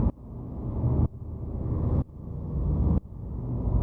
Index of /musicradar/sidechained-samples/125bpm